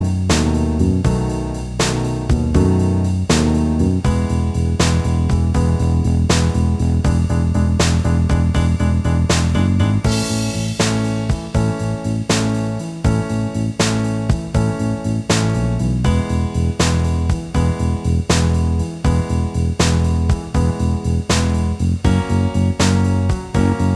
Minus Guitars Pop (1960s) 2:36 Buy £1.50